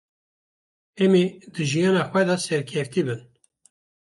/bɪn/